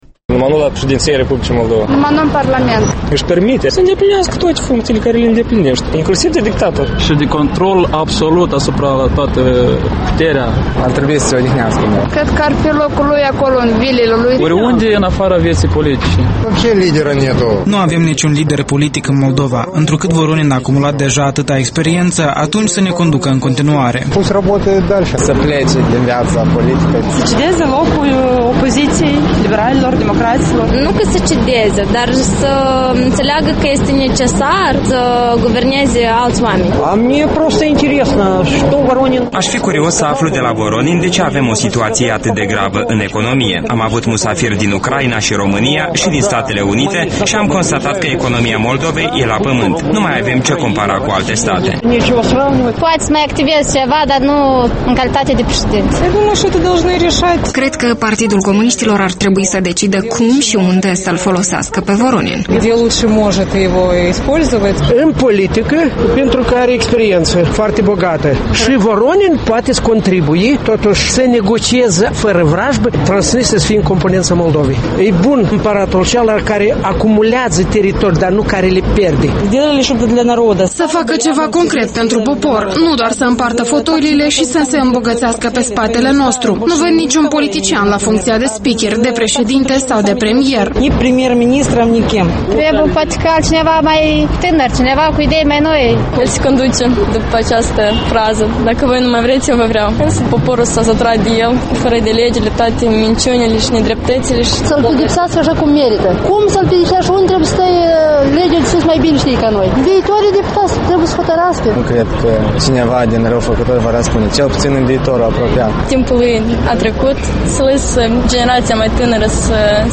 Vox pop despre viitorul politic al lui Vl. Voronin